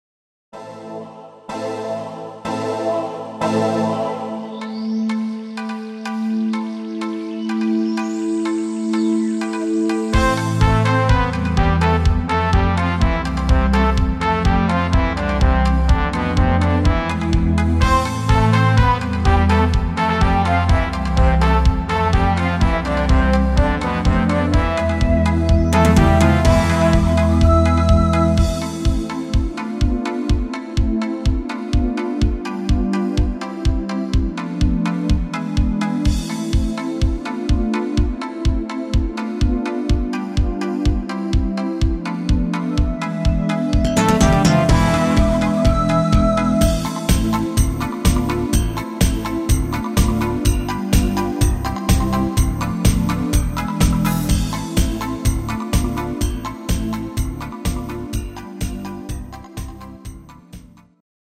russisches Lied